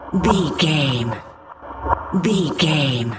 Electronic loops, drums loops, synth loops.,
Fast paced
Ionian/Major
Fast
aggressive
epic
futuristic
industrial
driving
energetic
hypnotic